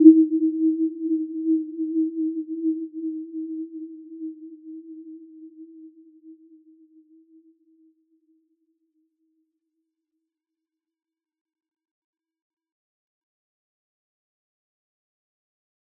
Warm-Bounce-E4-f.wav